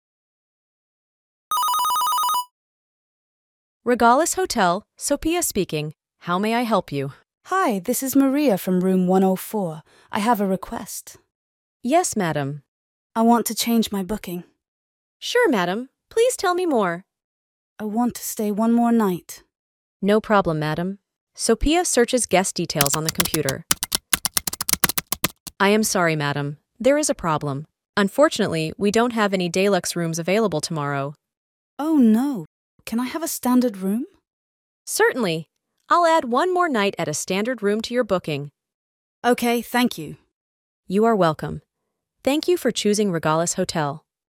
Listening-Check-1-Dialogue.m4a